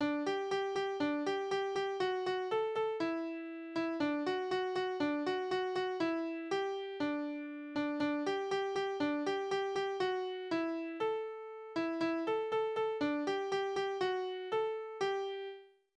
Kinderlieder: Bub und Spinne
Tonart: G-Dur
Taktart: 2/4
Tonumfang: Quinte
Besetzung: vokal